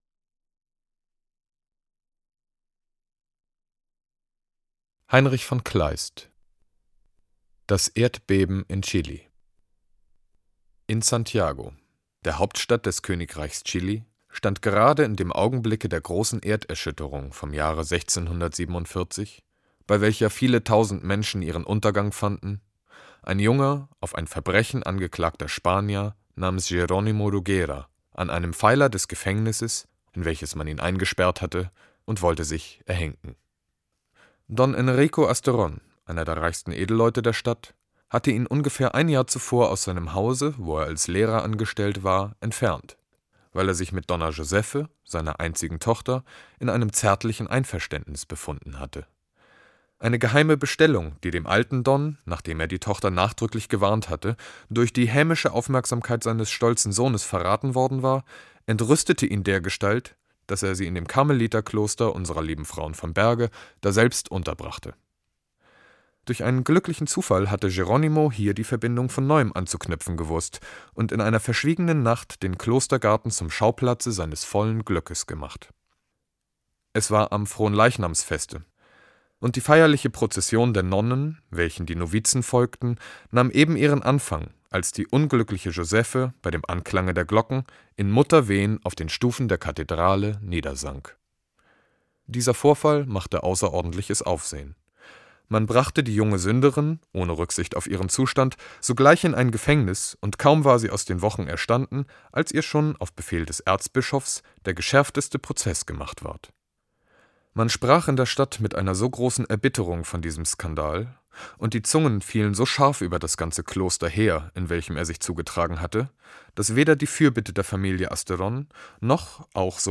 Text: Das Erdbeben von Chili Textauszug